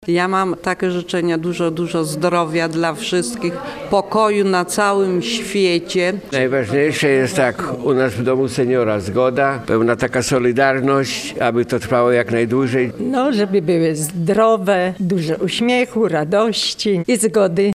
Seniorzy z Dziennego Domu Pobytu „Senior Plus” w Starogardzie Gdańskim złożyli życzenia wielkanocne słuchaczom Radia Gdańsk.